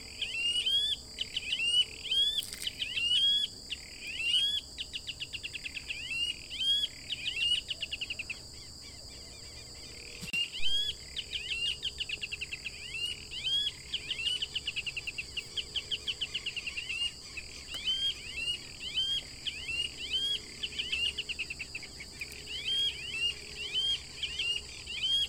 Gallineta Negruzca (Pardirallus nigricans)
Nombre en inglés: Blackish Rail
Fase de la vida: Adulto
Localidad o área protegida: Reserva Natural Urutaú
Condición: Silvestre
Certeza: Observada, Vocalización Grabada